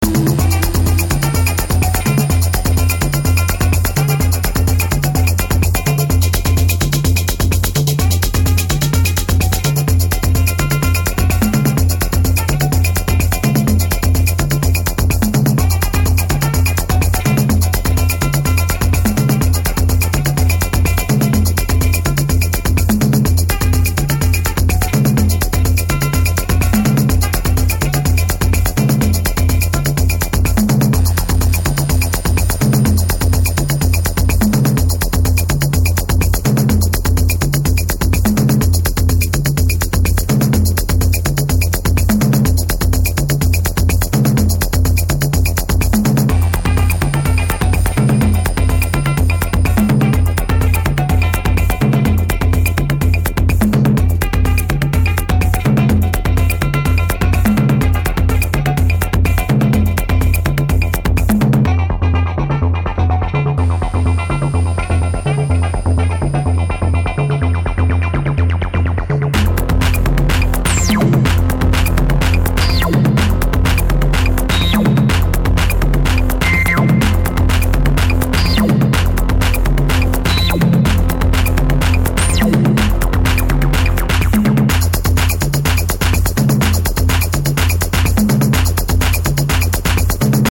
】アシッド・ハウスmeetsアラビアン・サウンド！